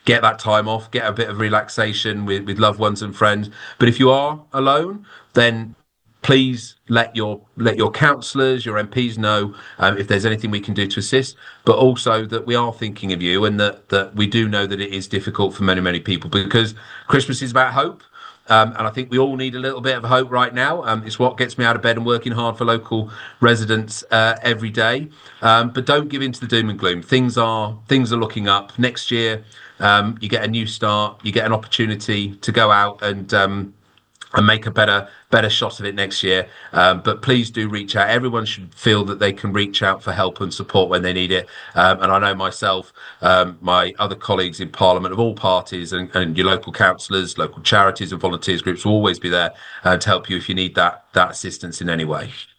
In his annual Radio Jackie Christmas message, Sutton and Cheam’s Lib Dem MP, Luke Taylor, says things are “looking up.””
LUKE-TAYLOR-CHRISTMAS-MESSAGE.wav